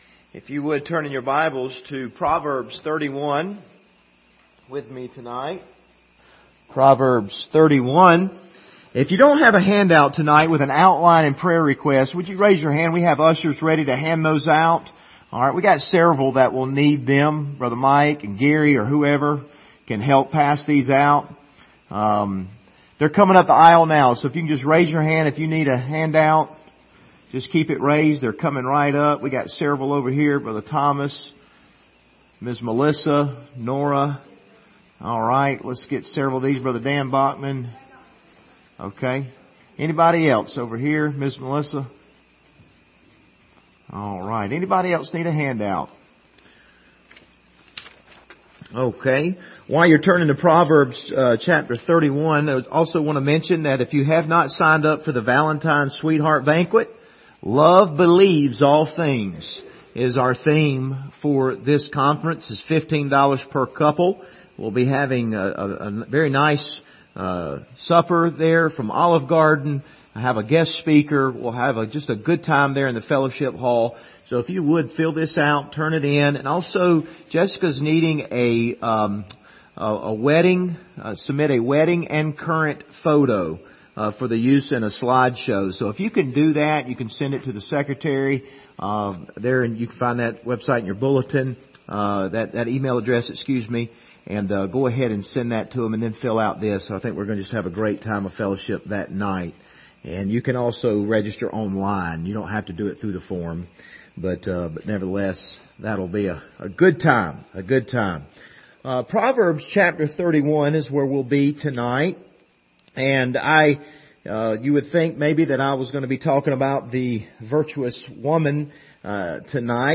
Proverbs 31:26 Service Type: Wednesday Evening « The Greatest Need of the Hour Going Forward by Faith